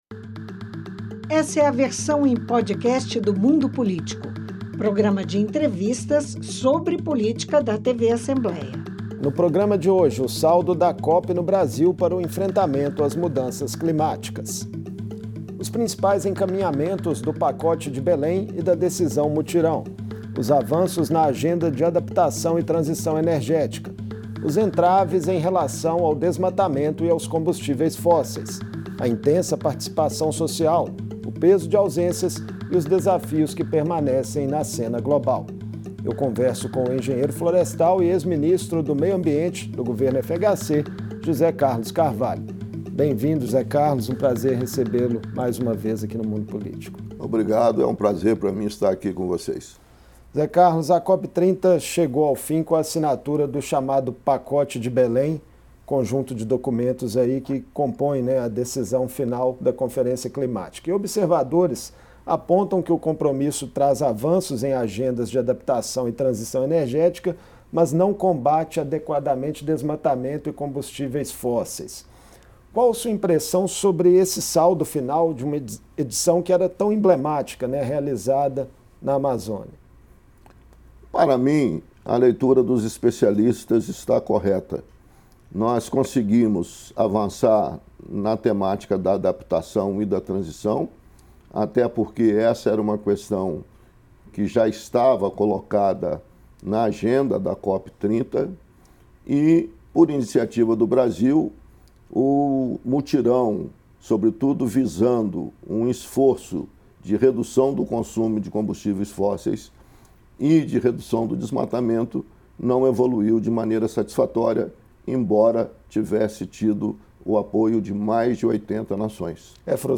Entre eles, o engenheiro florestal, ex-secretário de Estado de Meio Ambiente e ex-ministro do Meio Ambiente do governo Fernando Henrique, José Carlos Carvalho que conversa